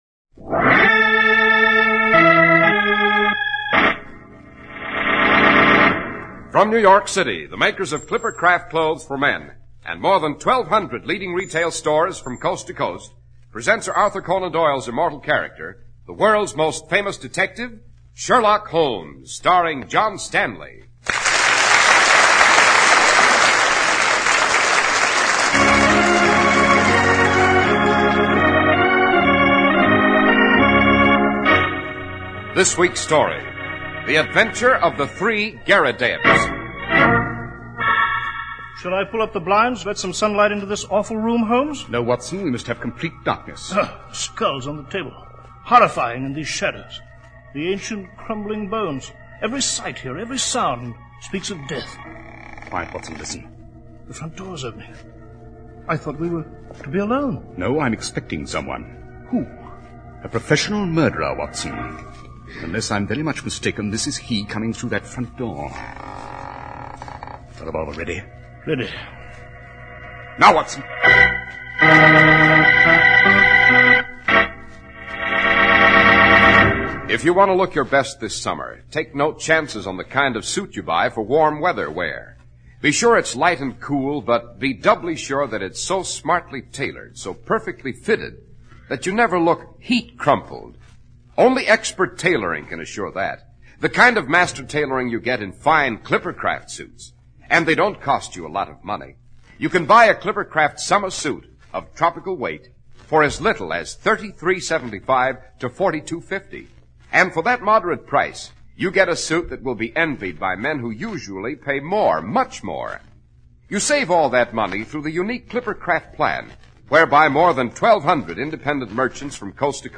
Radio Show Drama with Sherlock Holmes - The Three Garridebs 1949